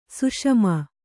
♪ suṣama